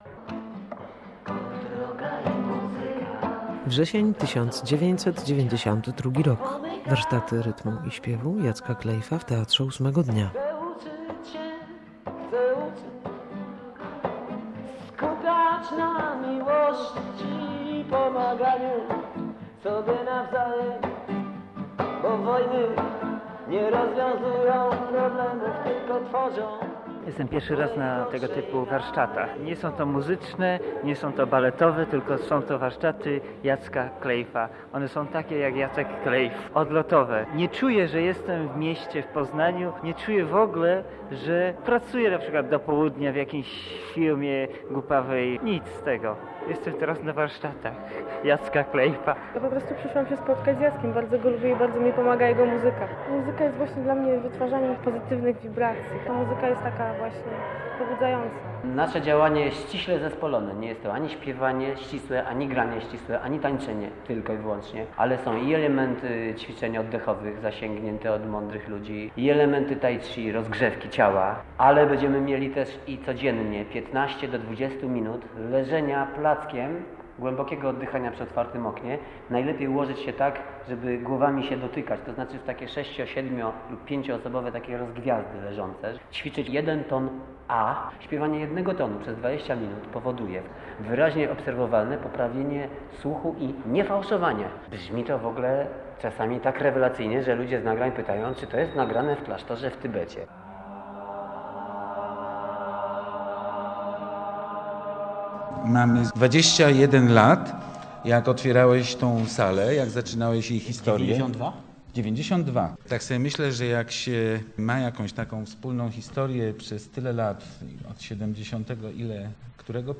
"Kleyff w Ósemkach" - reportaż